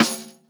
Boom-Bap Snare 84.wav